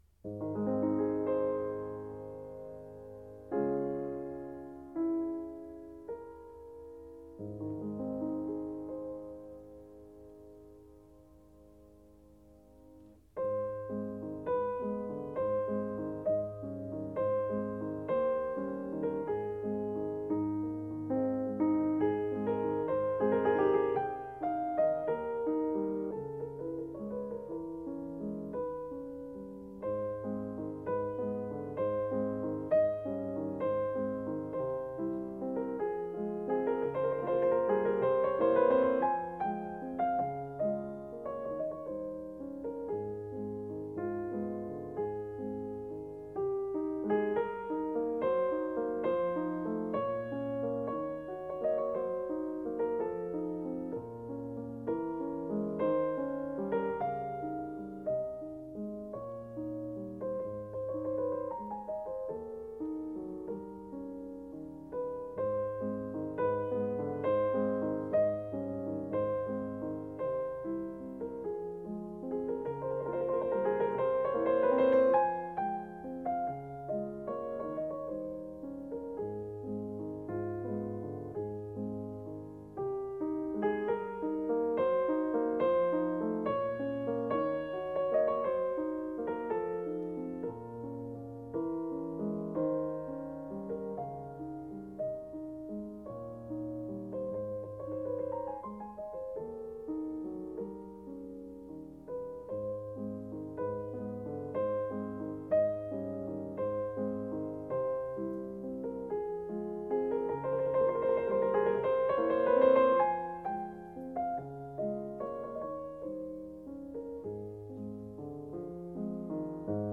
10. 降A大调，OP32.2，作于1836-1837年，献给德.毕林男爵夫人，慢板，三段形式。